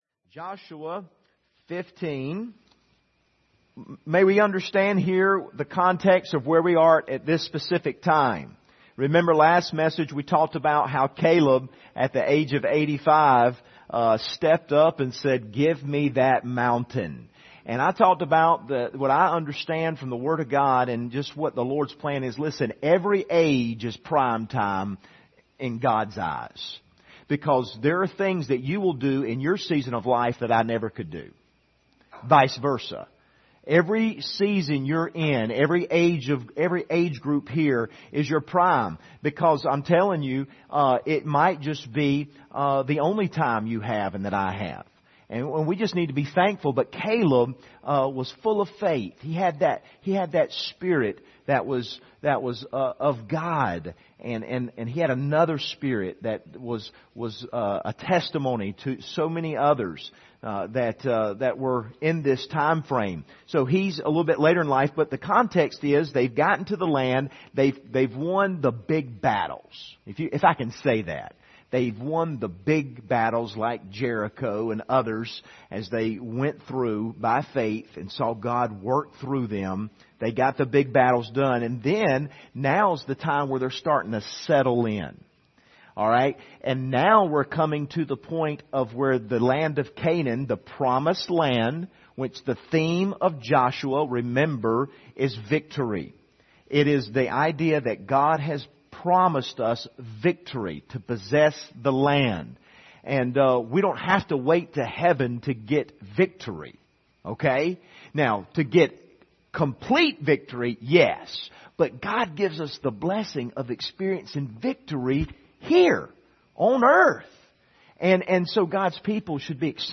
Joshua 15-17 Service Type: Sunday Evening « We Have a Helper